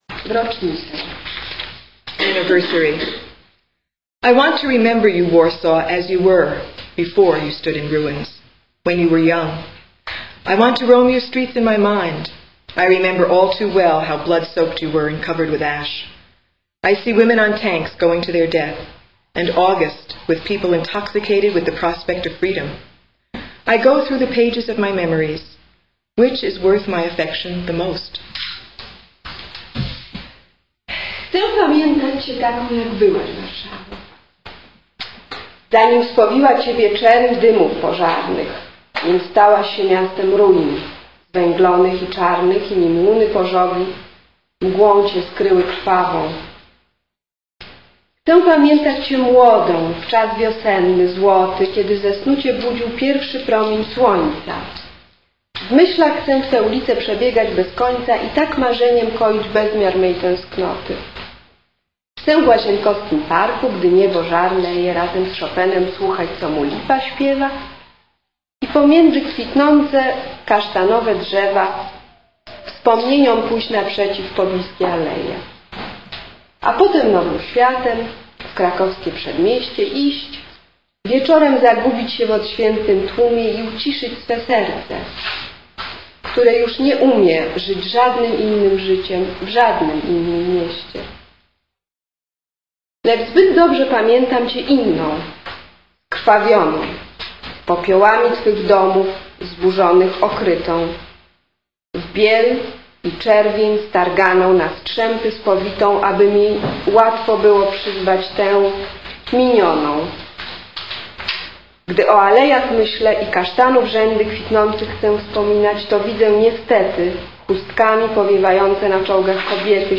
A reading of original poetry
Jones Library Amherst, MA.